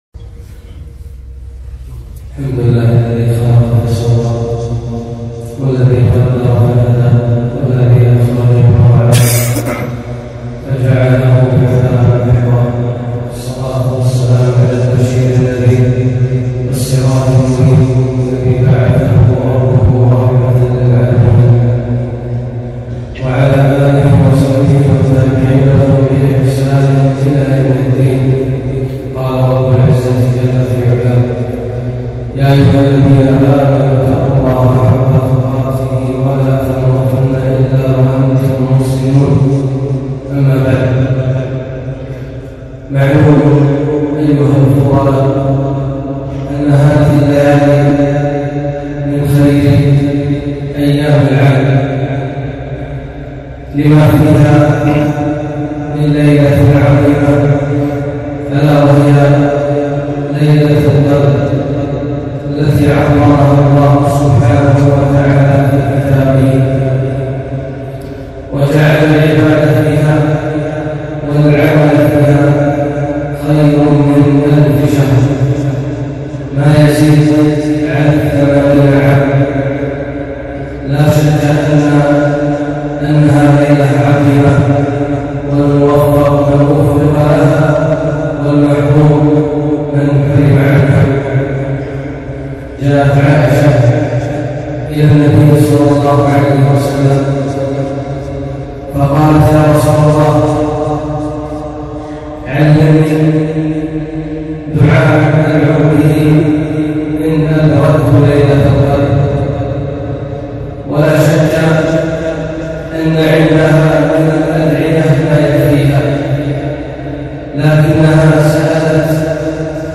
خطبة - ( اللهم إنك عفو تحب العفو فاعف عنا ) - دروس الكويت